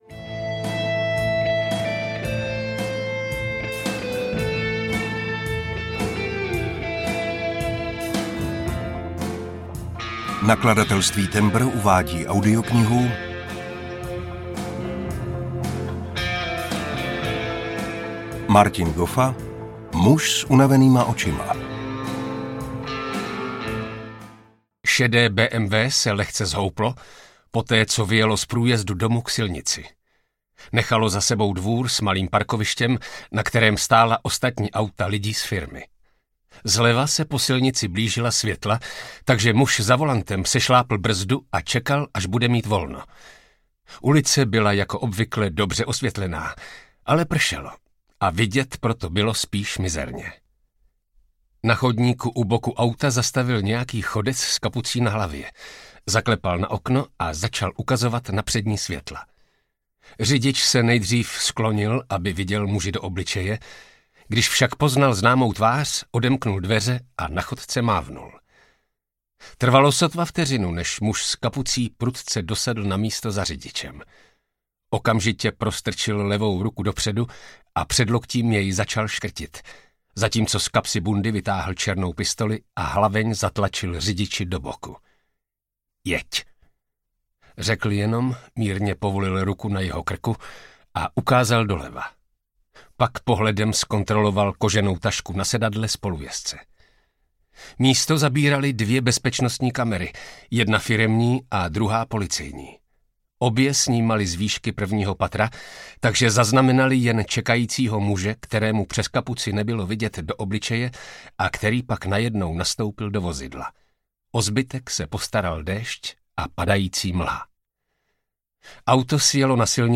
Muž s unavenýma očima audiokniha
Ukázka z knihy